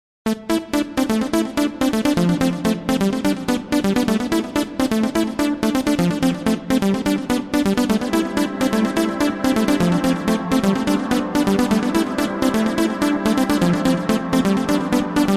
Effets Sonores ,SMS